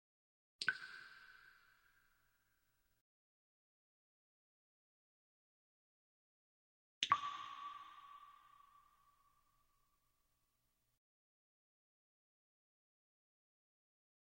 Similar to track 28 (water drops)